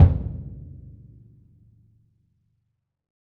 BDrumNewhit_v5_rr1_Sum.wav